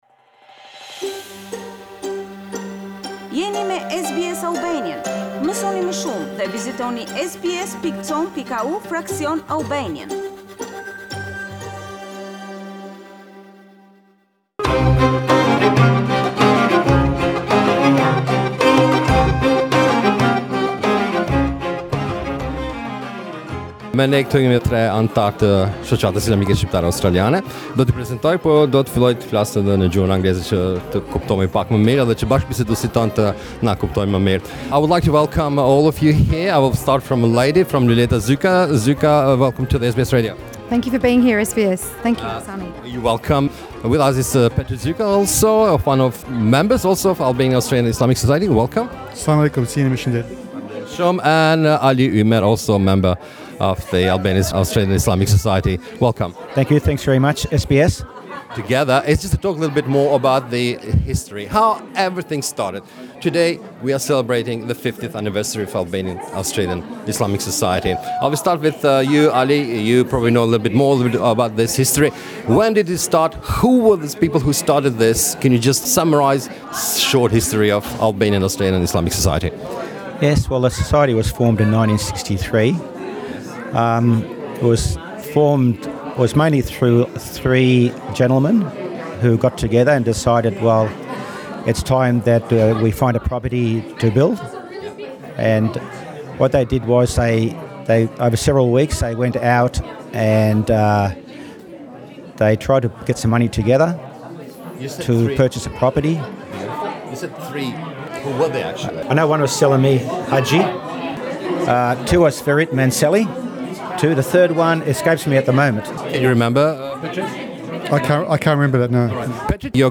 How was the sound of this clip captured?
Radio SBS was present at festival and here is what we recorded on that day.